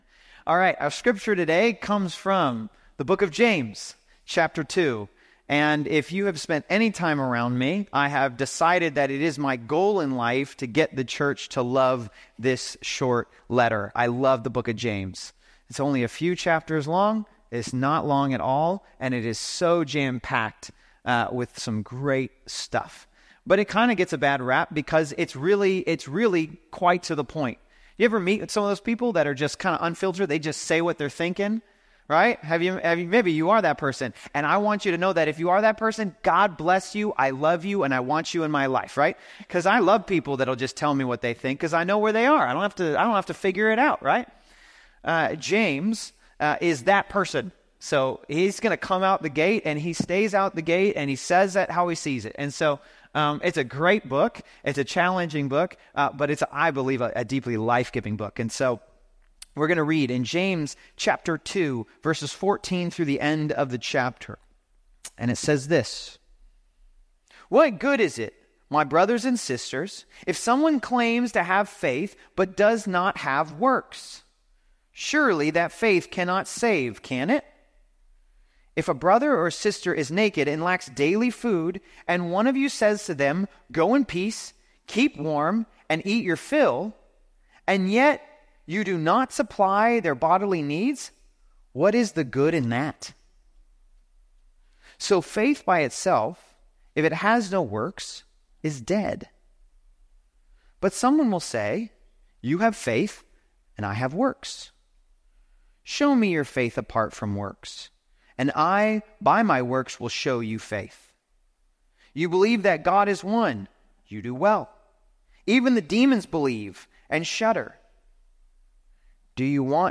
Service Type: The Source